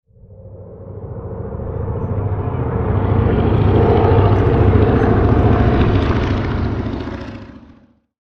Slow Passing Older Airplane Sound Effect
Realistic sound of an older aircraft flying by with a diesel engine, capturing the engine roar as it approaches and flies away.
Slow-passing-older-airplane-sound-effect.mp3